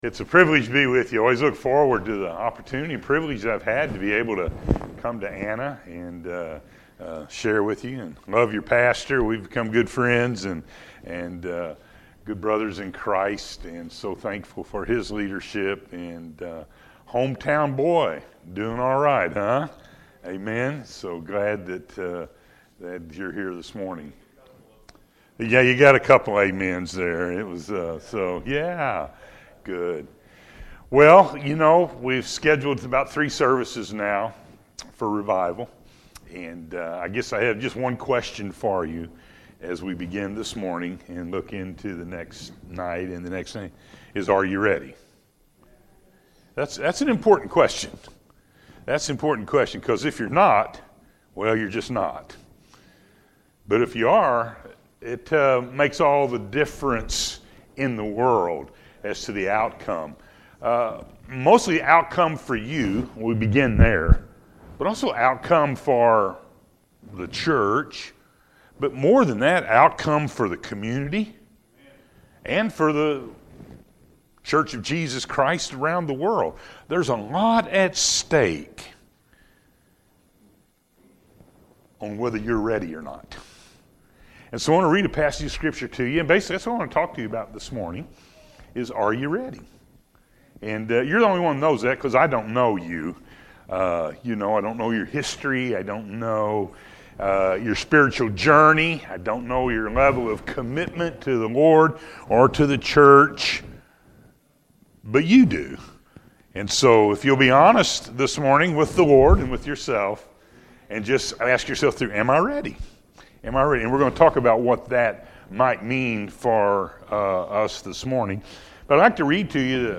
Are You Ready?-A.M. Service